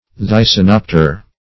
Thysanopter \Thy`sa*nop"ter\, n.